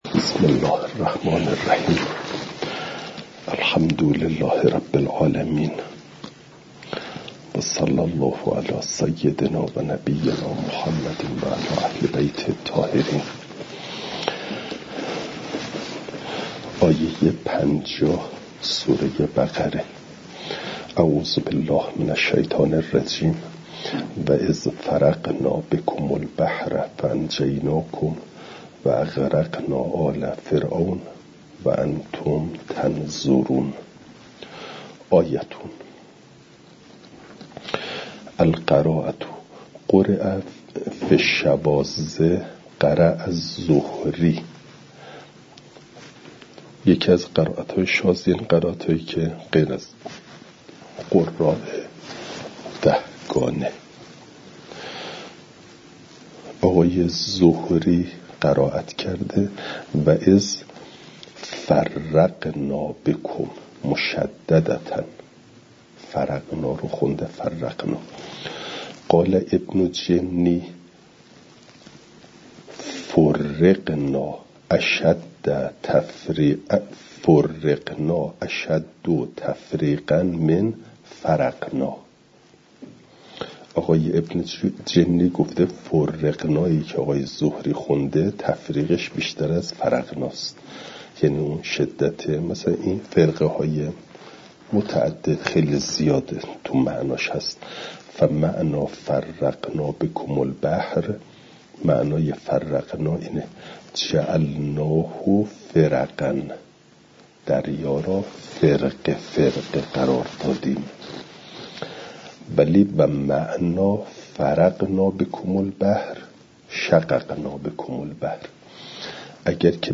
فایل صوتی جلسه شصتم درس تفسیر مجمع البیان